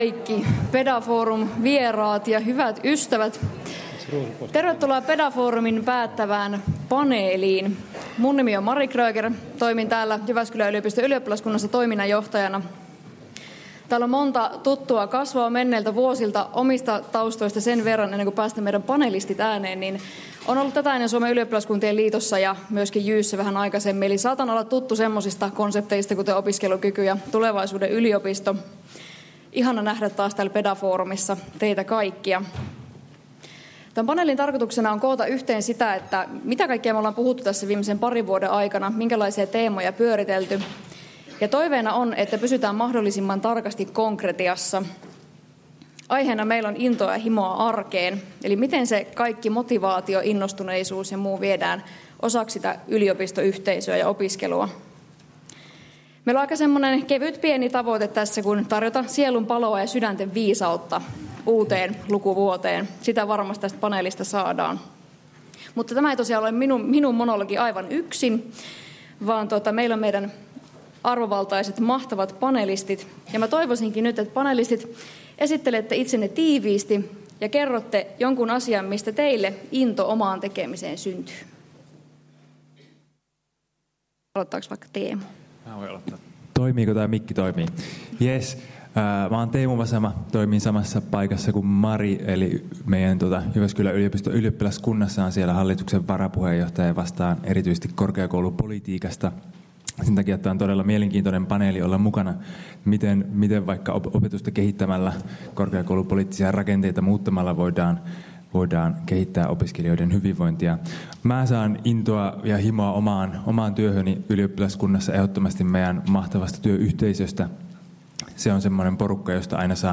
Päätöspaneeli